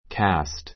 cast kǽst キャ スト 動詞 三単現 casts kǽsts キャ スツ 過去形・過去分詞 cast -ing形 casting kǽstiŋ キャ ス ティン ぐ ⦣ 原形・過去形・過去分詞がどれも同じ形であることに注意. ❶ 投げる ⦣ 次の句以外ではふつう throw を使う. cast dice cast dice さいころを投げる[振る] The die is cast.